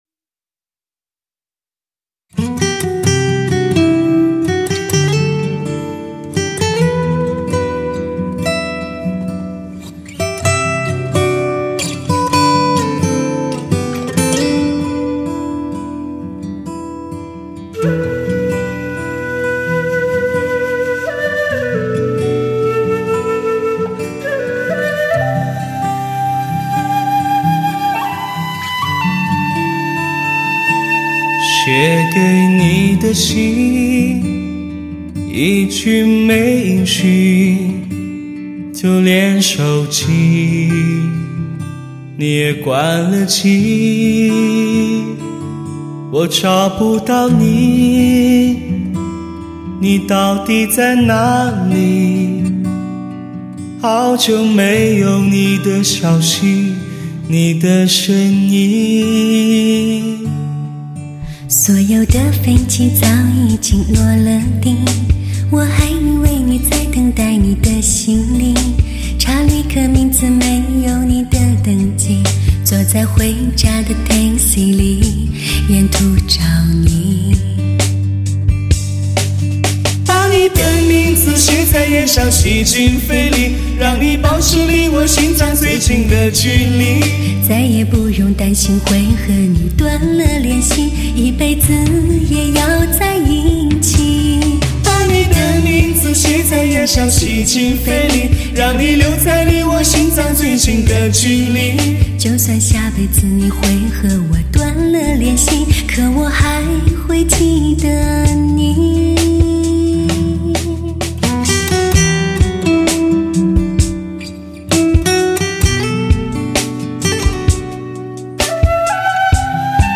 30首甜蜜对唱情歌演绎男女间的情感世界